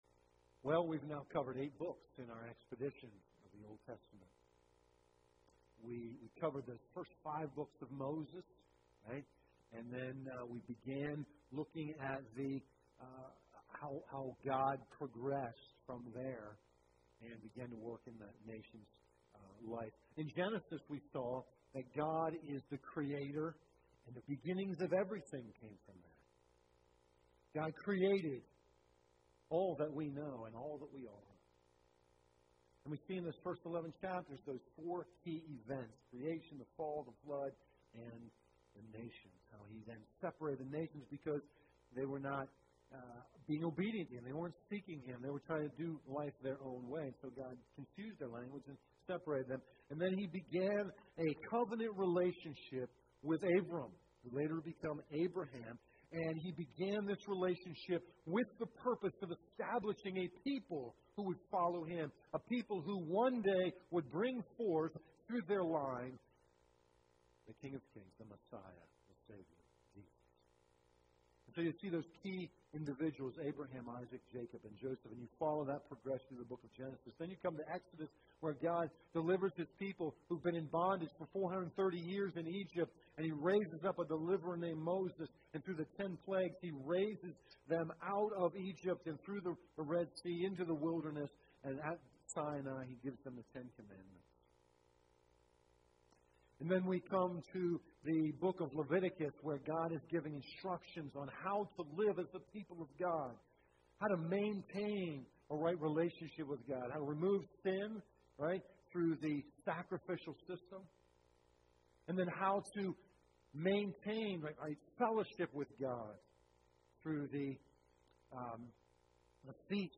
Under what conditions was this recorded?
Passage: Ecclesiastes 7:1-29 Service Type: Morning Service